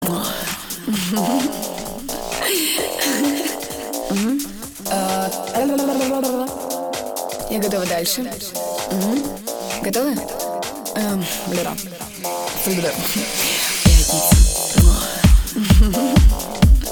• Качество: 256, Stereo
веселые
dance